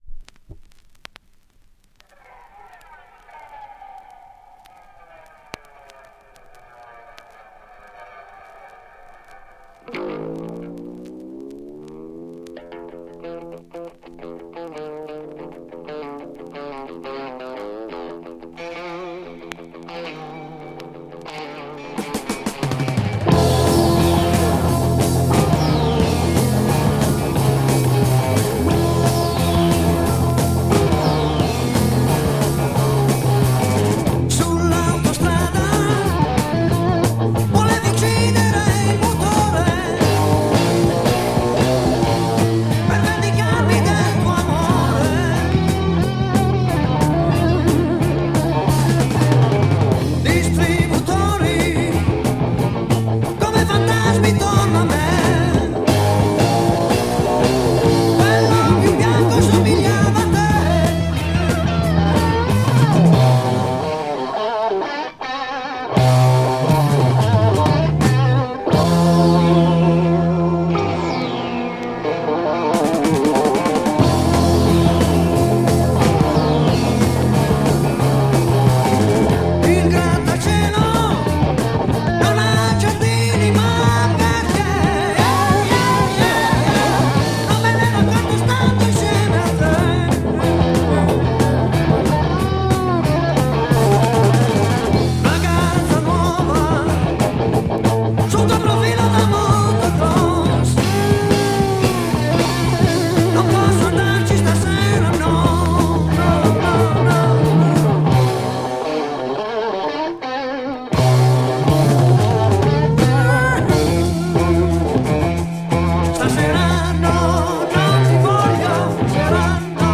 Italian prog rock